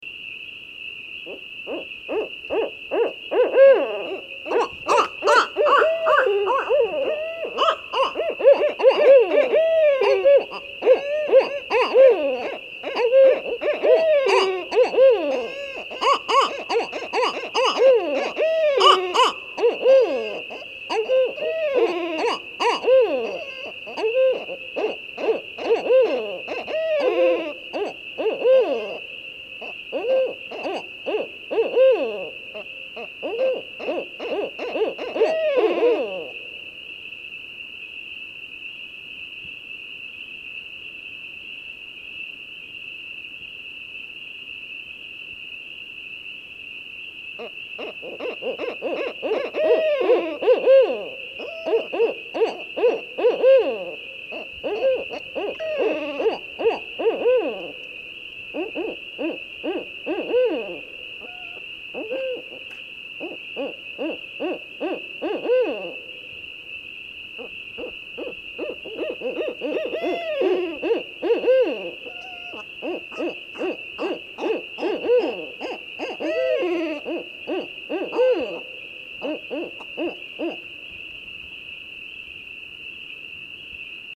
“The Barred Owl (Strix varia) is a large typical owl native to North America.
The usual call is a series of eight accented hoots ending in oo-aw, with a downward pitch at the end.
When agitated, this species will make a buzzy, rasping hiss.